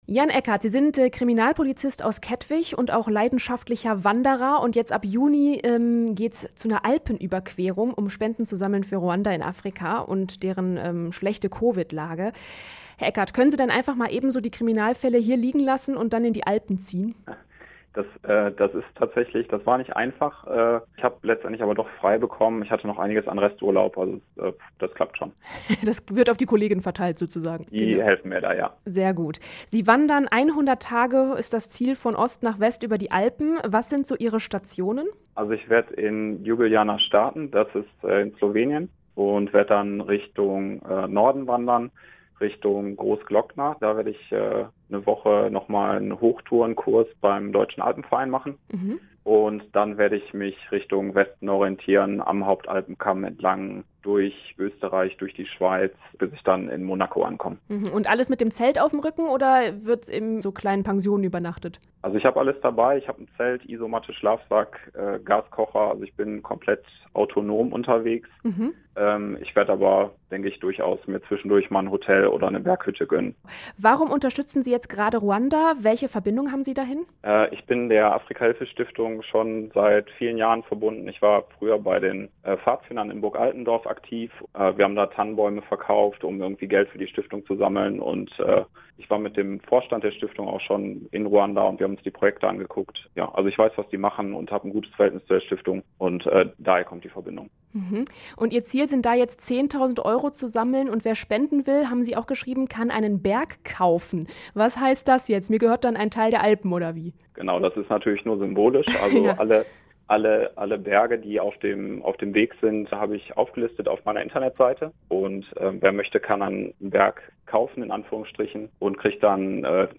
Ein Polizist aus Essen wandert durch die Alpen und sammelt so Spenden für Ruanda. Im Radio Essen-Interview erzählt er von einer harten Tour.